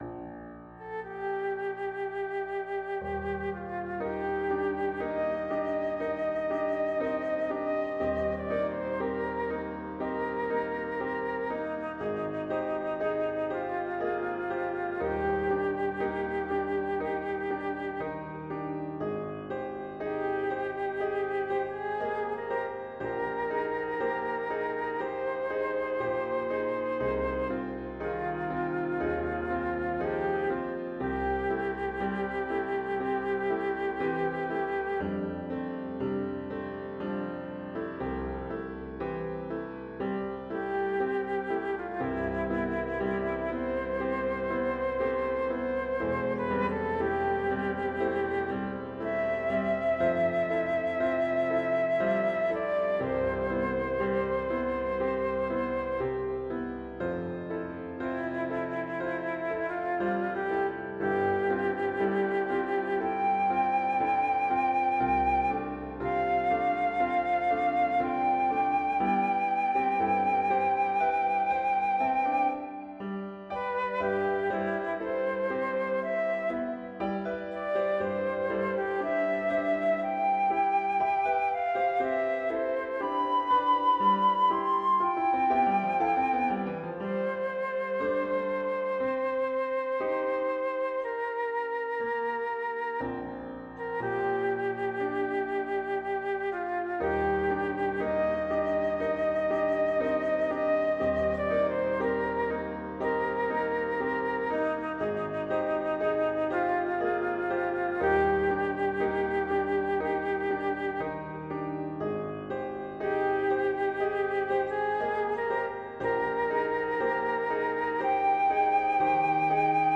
The fourth is marked adagio, lightly shifting meters and tonal domains underpin a long-lined melody for flute.